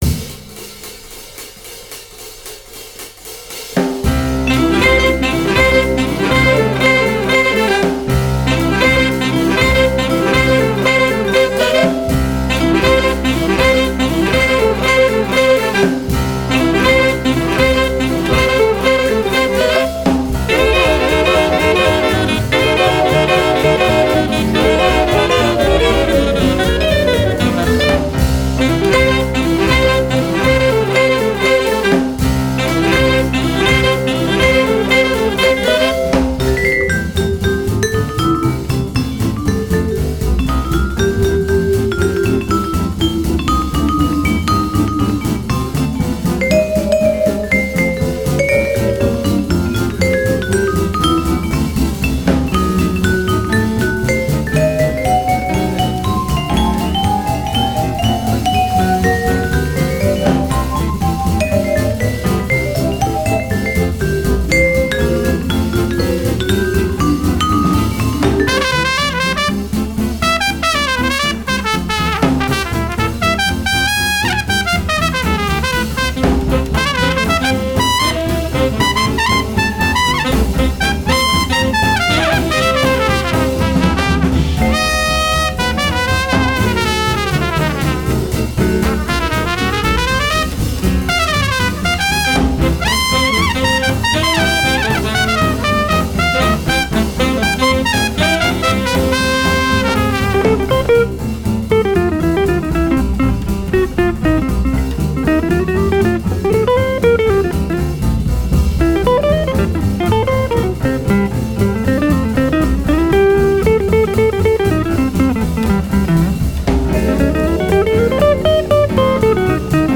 trompette
sax alto
sax ténor
vibraphone
piano
guitare
contrebasse
batterie